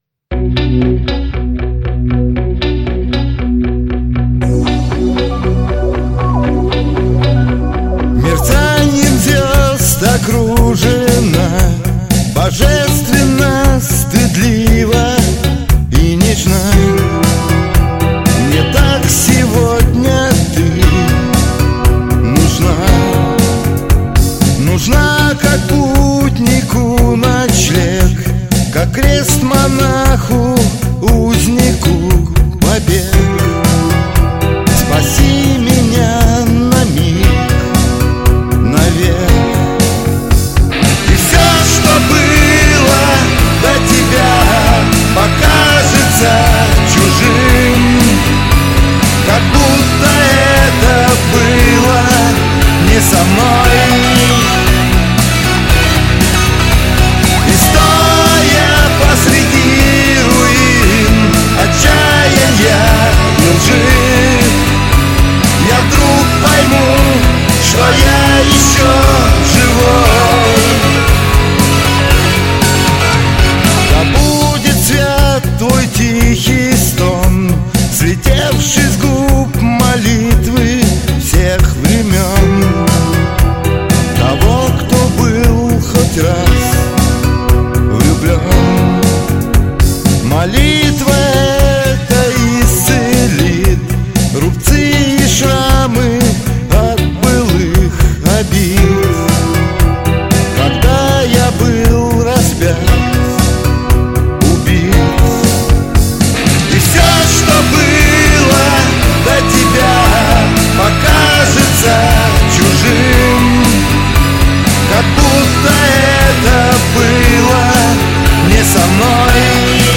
больше ближе к року